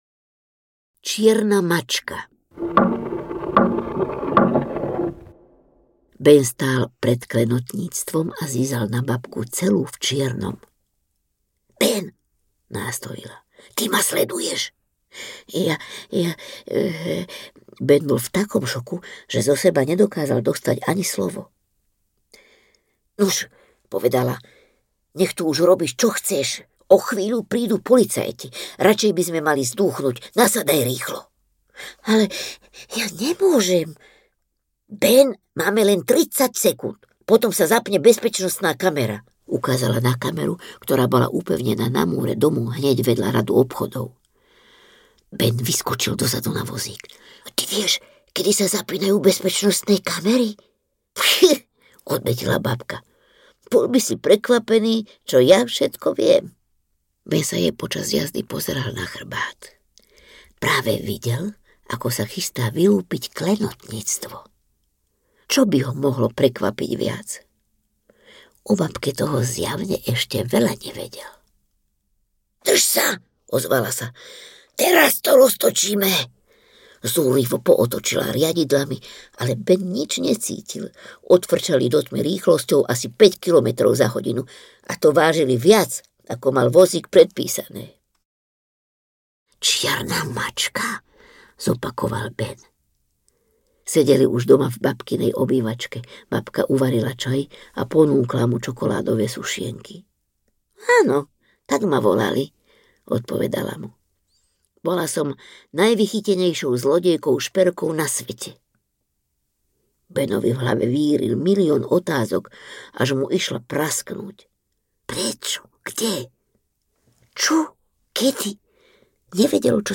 Babka Gaunerka audiokniha
Ukázka z knihy
babka-gaunerka-audiokniha